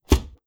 Close Combat Attack Sound 3.wav